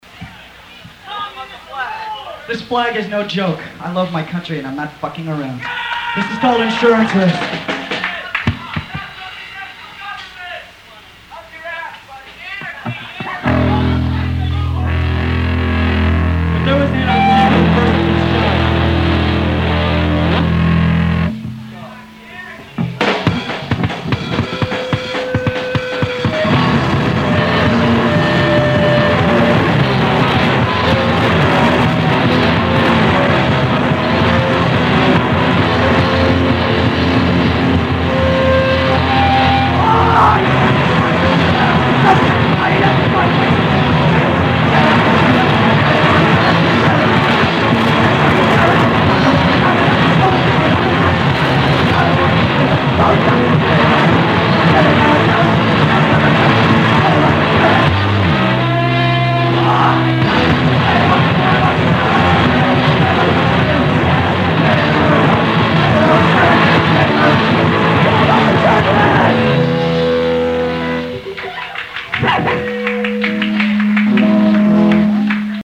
Boston Love Hall Show